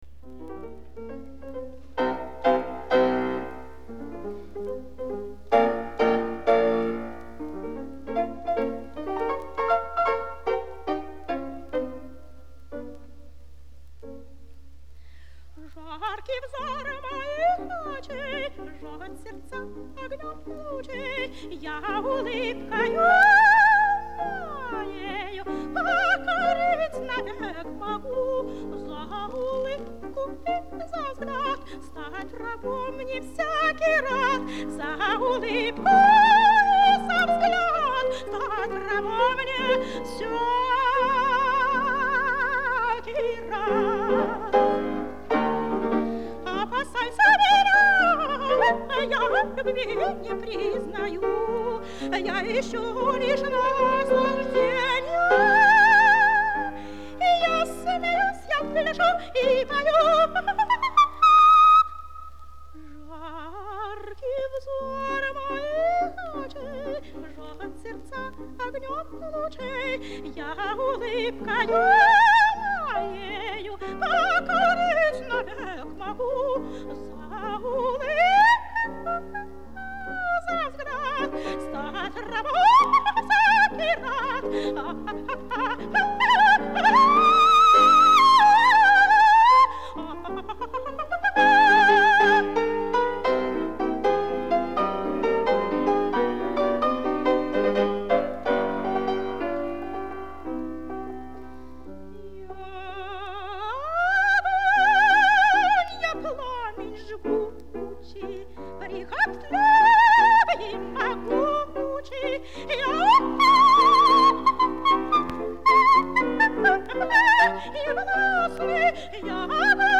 фортепьяно